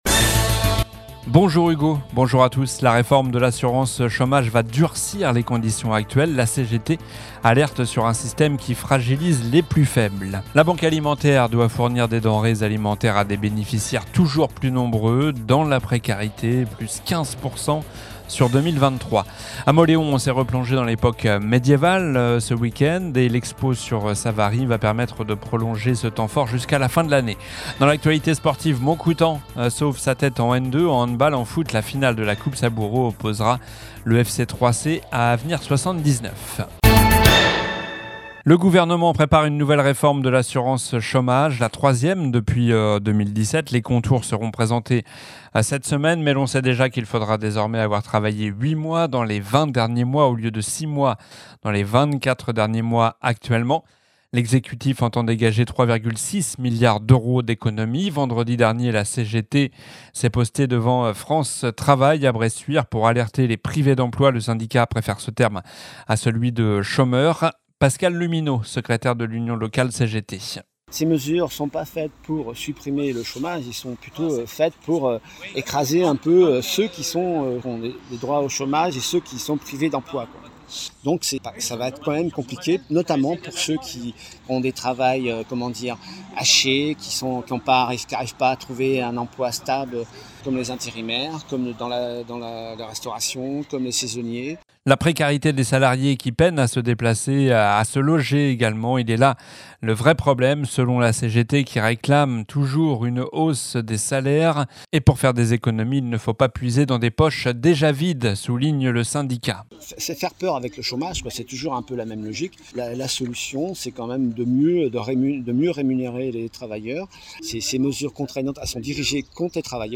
Journal du lundi 27 mai (midi)